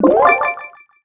pickup_spawn_02.wav